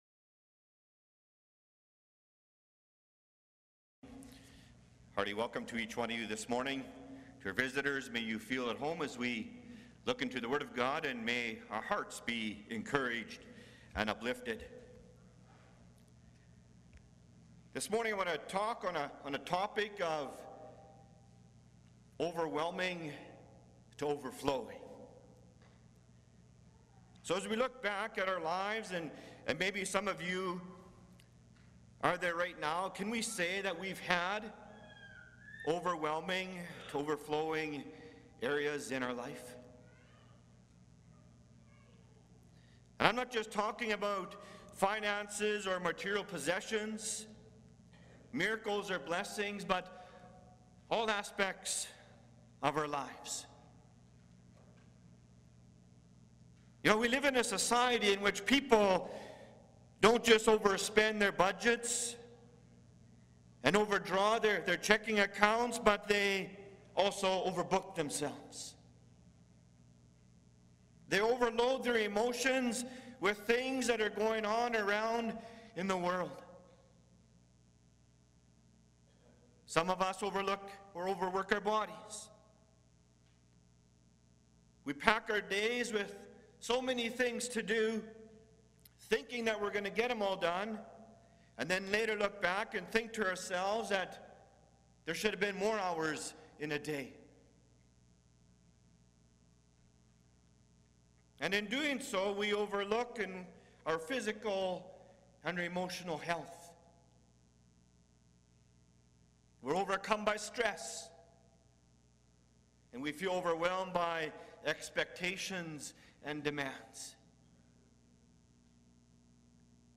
Passage: 2 Kings 2:1-10 Service Type: Sunday Morning « The Power and Conviction of the Word of God Church Bible Study